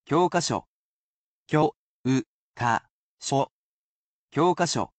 I read the word aloud for you, sounding out each mora.